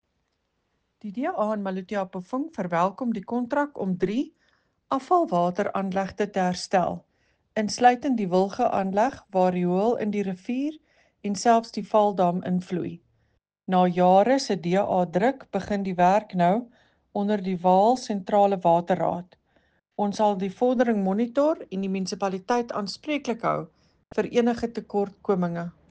Afrikaans soundbites by Cllr Eleanor Quinta and Sesotho soundbite by Cllr Ana Motaung.